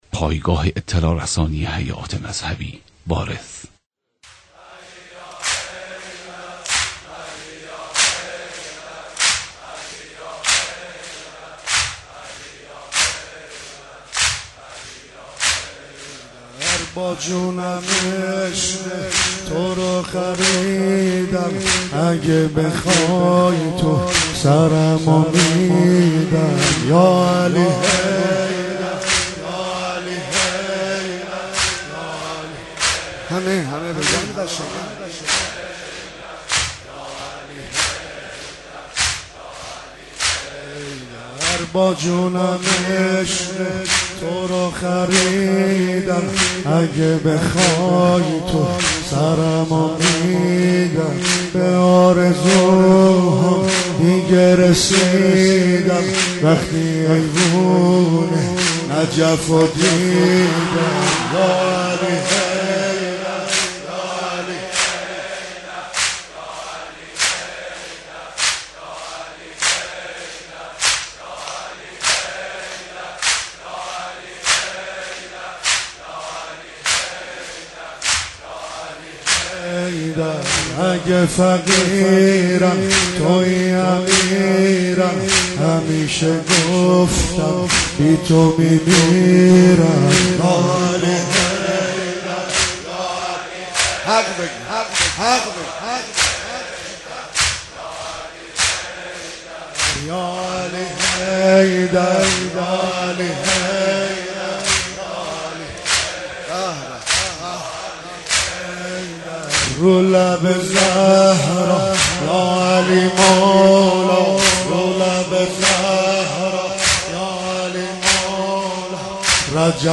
مداحی حاج عبدالرضا هلالی به مناسبت شهادت امیرالمومنین (ع)